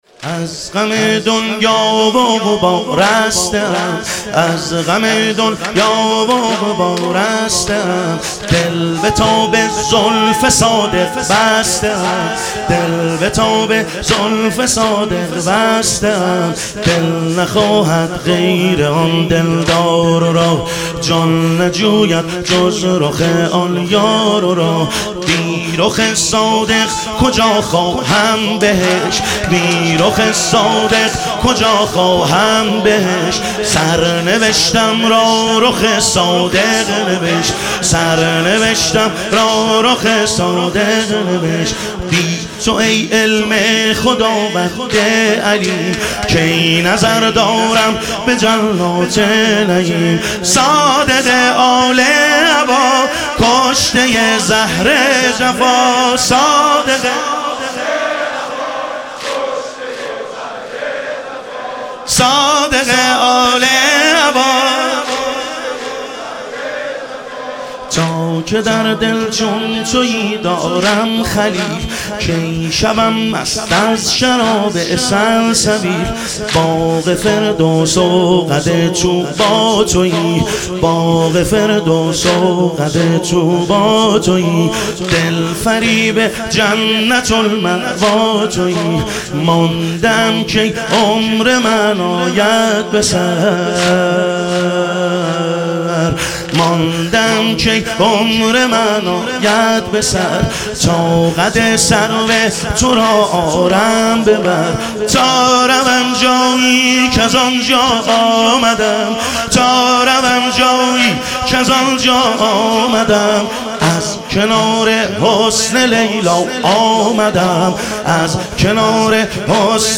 شهادت امام صادق علیه السلام - واحد